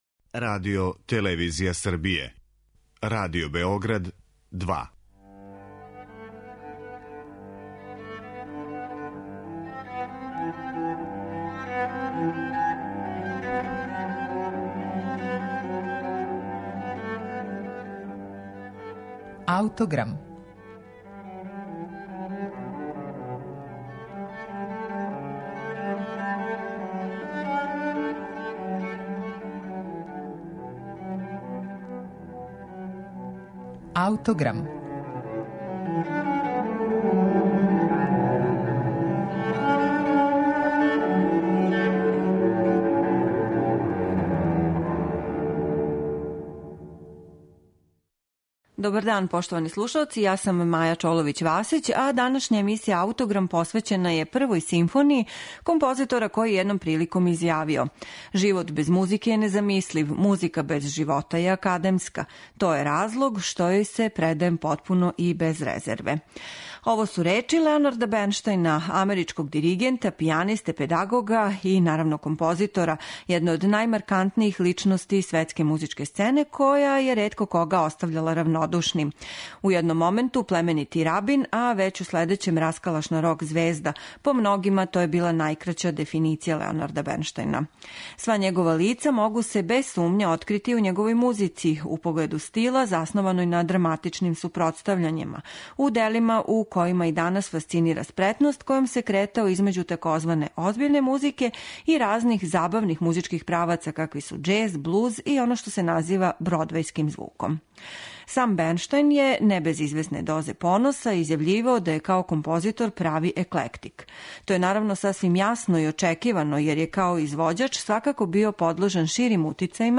за сопран и оркестар